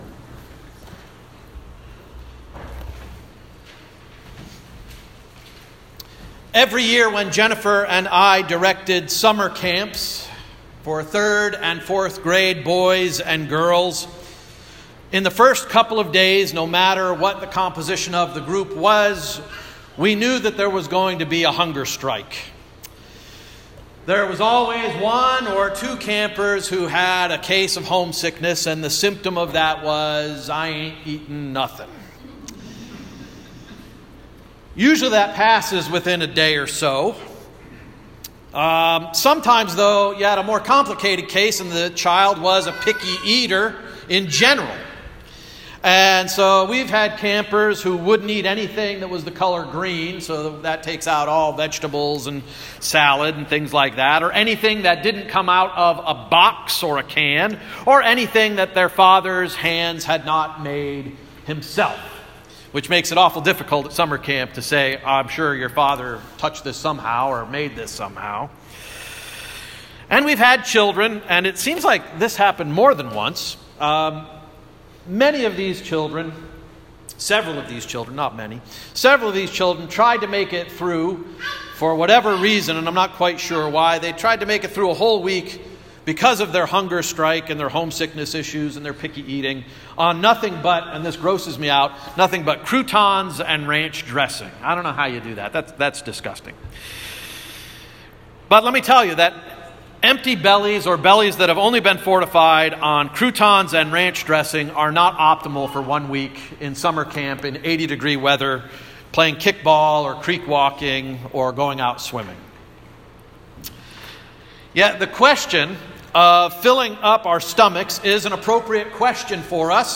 Sermon of October 7, 2018 — “Full Bellies/ Full Souls” Worldwide Communion Sunday